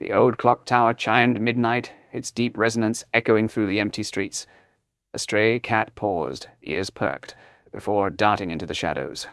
XTTS_V2_Finetuned_Voice_Cloning